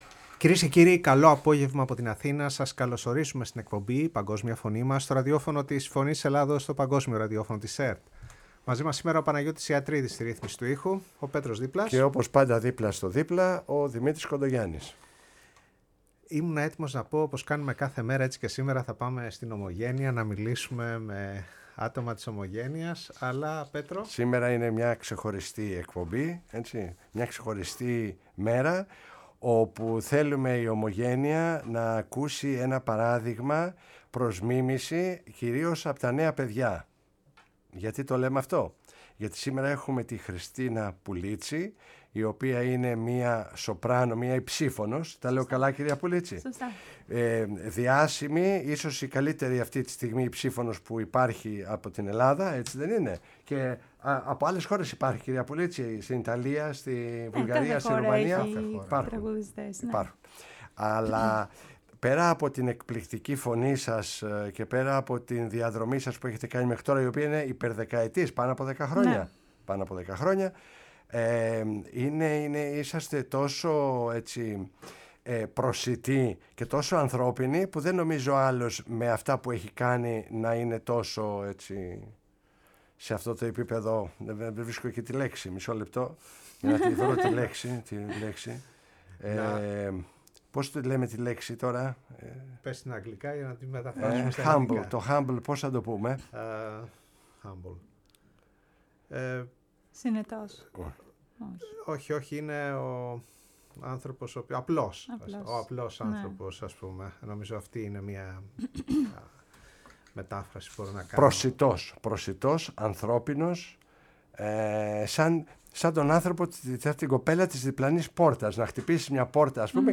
μίλησε για την ζωή της και την επαγγελματική της πορεία από μικρό παιδί μέχρι σήμερα στο ραδιόφωνο της Φωνής της Ελλάδας